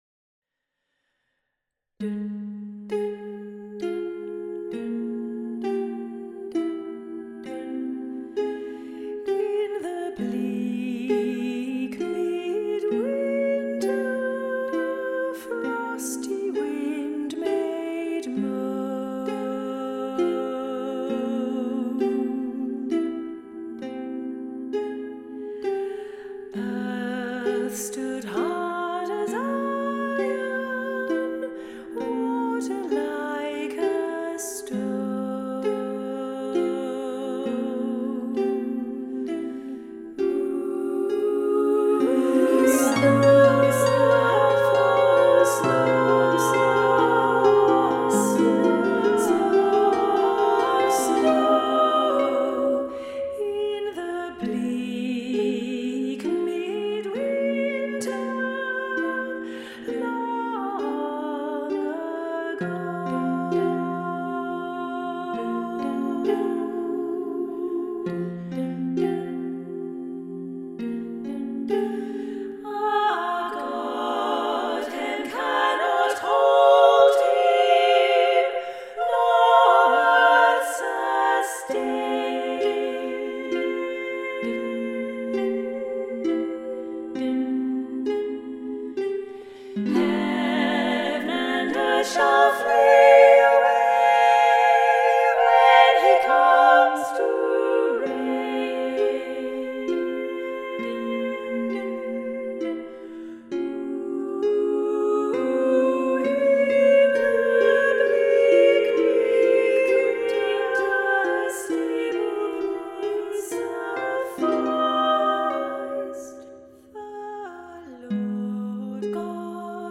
Voicing: "SSAA"